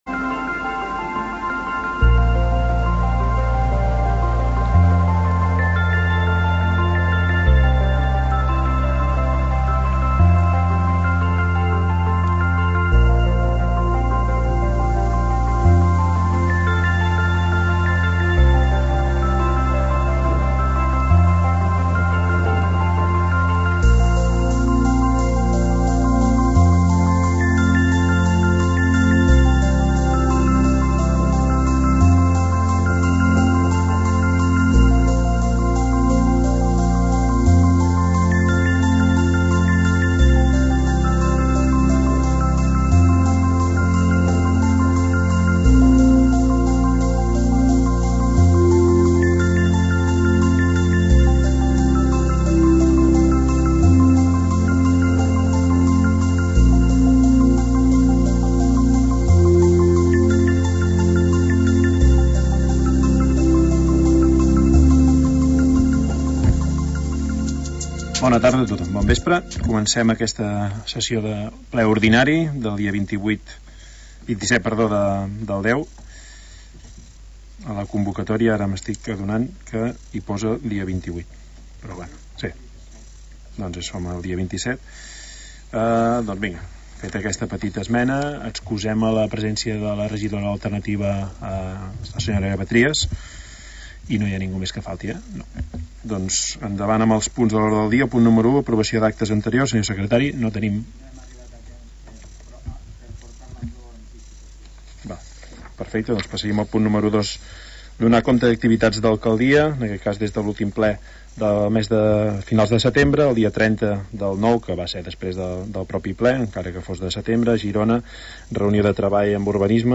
Informatiu que desplega una mirada àmplia sobre el territori, incorporant la informació de proximitat al relat de la jornada.